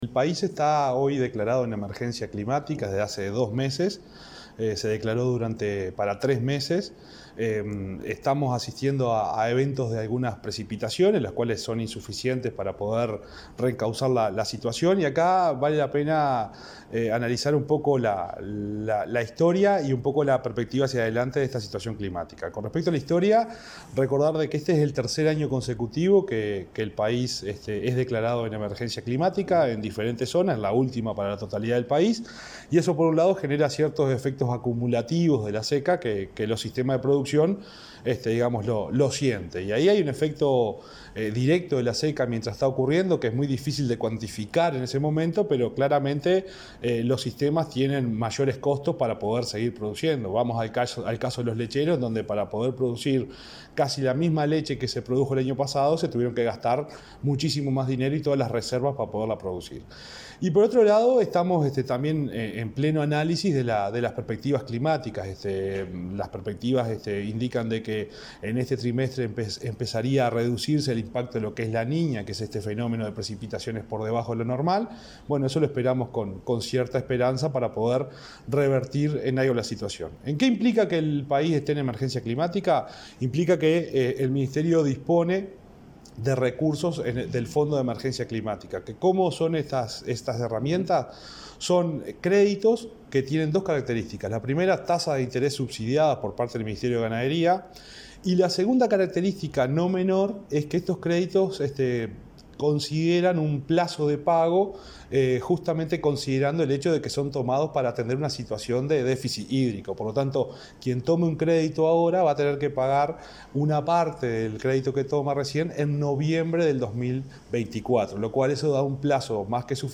Entrevista al subsecretario de Ganadería, Juan Ignacio Buffa